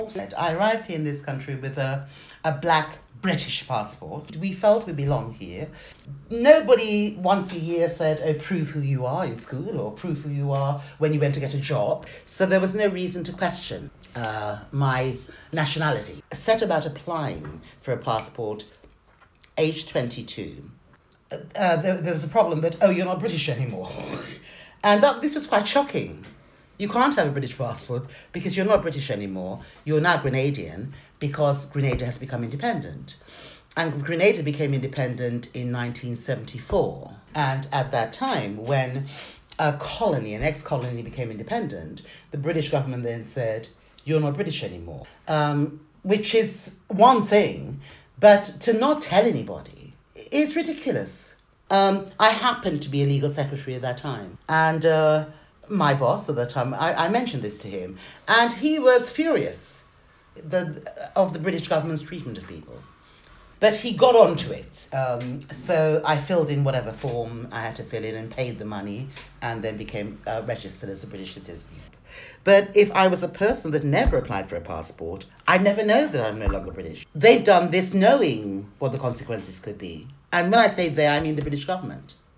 interviewee
This oral history excerpt has been drawn from the scoping project ‘Nationality, Identity and Belonging: An Oral History of the ‘Windrush Generation’ and their Relationship to the British State, 1948-2018'.